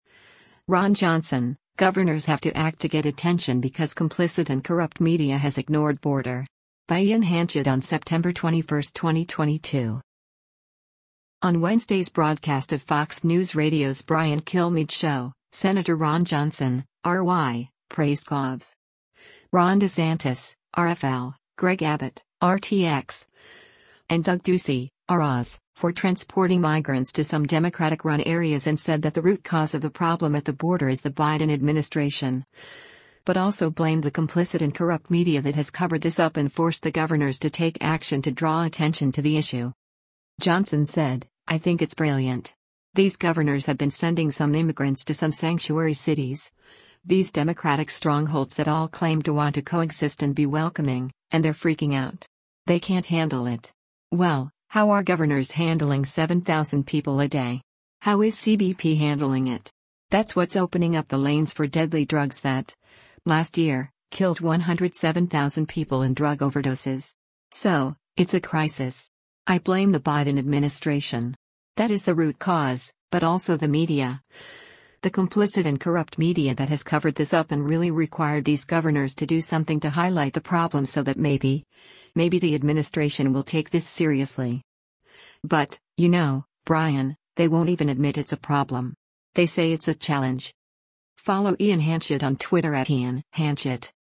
On Wednesday’s broadcast of Fox News Radio’s “Brian Kilmeade Show,” Sen. Ron Johnson (R-WI) praised Govs. Ron DeSantis (R-FL), Greg Abbott (R-TX), and Doug Ducey (R-AZ) for transporting migrants to some Democratic-run areas and said that the root cause of the problem at the border is the Biden administration, but also blamed “the complicit and corrupt media that has covered this up” and forced the Governors to take action to draw attention to the issue.